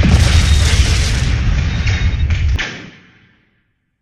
missilehit.ogg